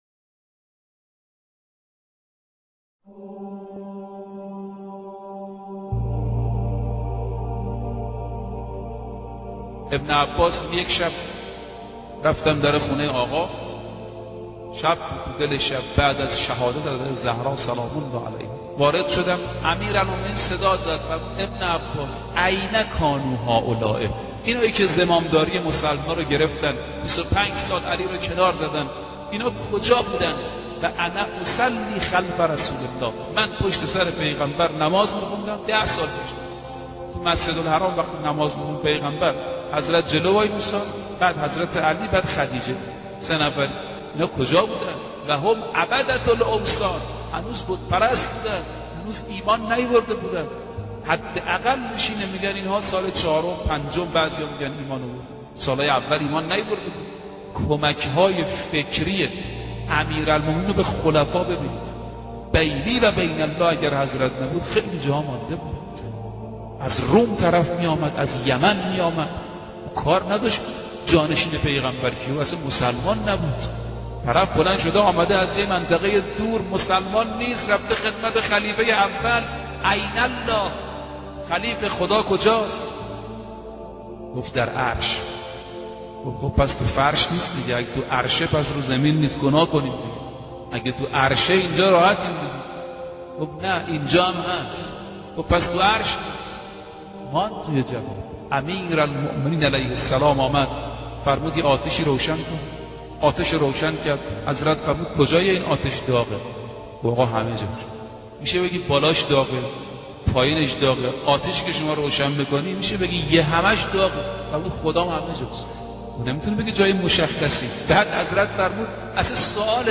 سخنرانی مذهبی